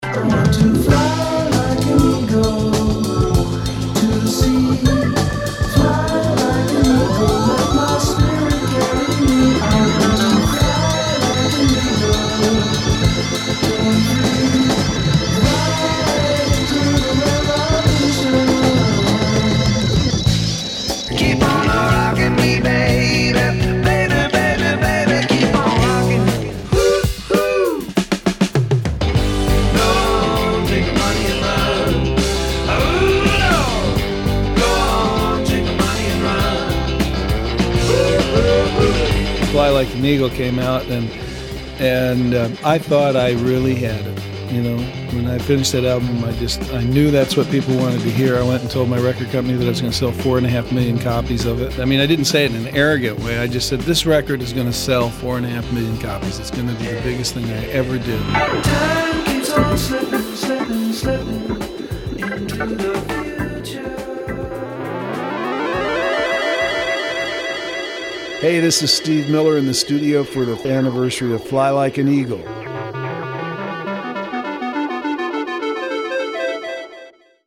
Steve Miller is my esteemed guest here In the Studio for the Fly Like an Eagle fiftieth anniversary beginning May 11.